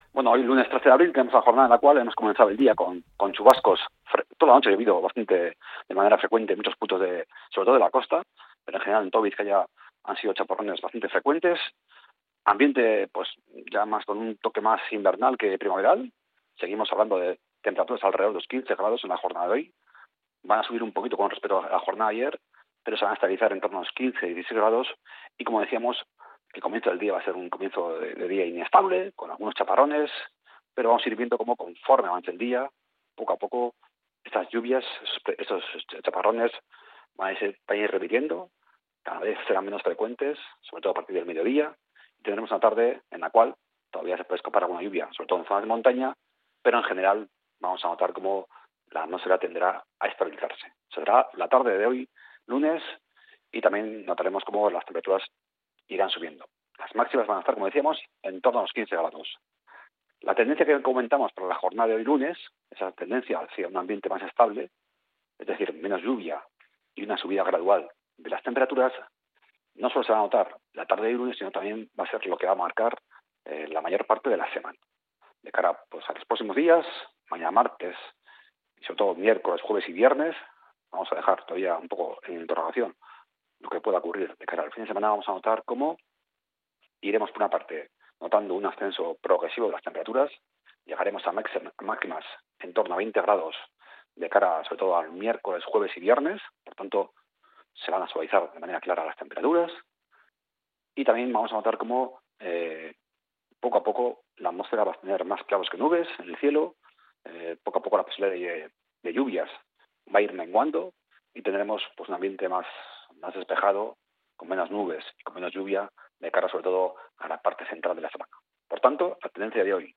El pronóstico del tiempo en Bizkaia para este 13 de abril